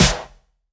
9SNARE7.wav